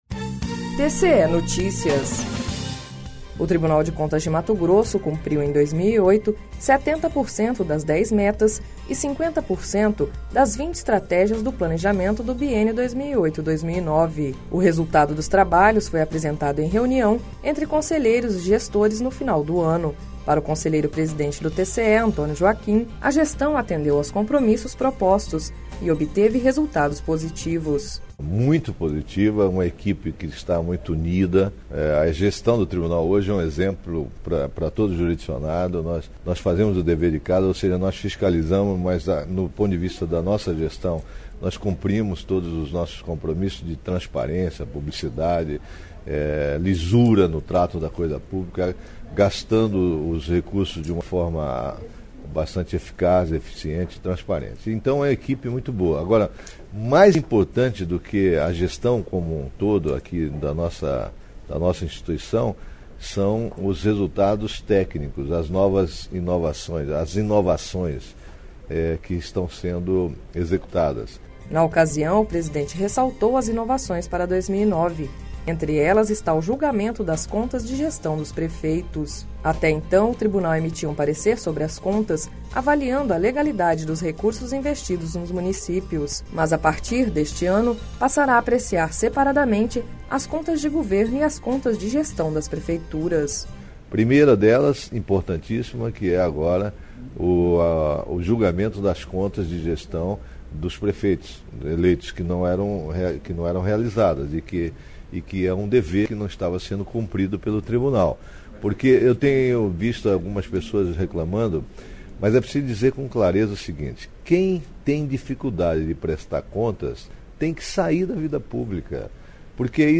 Sonora: Antonio Joaquim - conselheiro presidente do TCE-MT